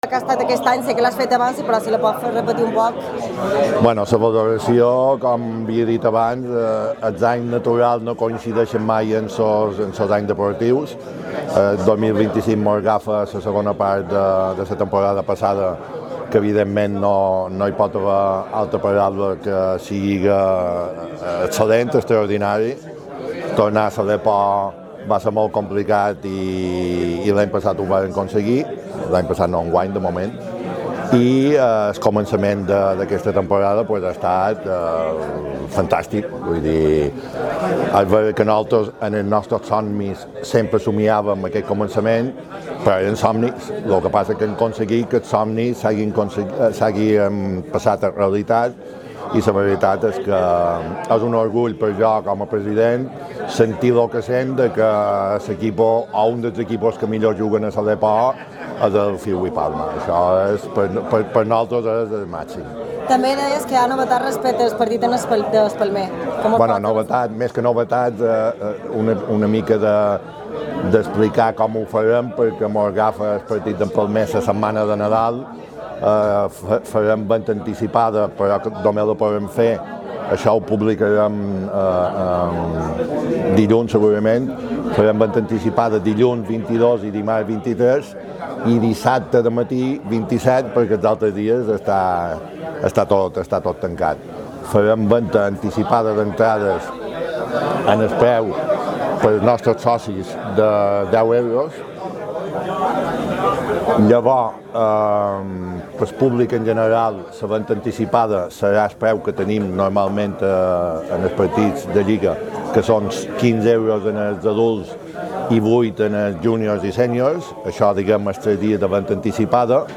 Declaraciones
después del brindis: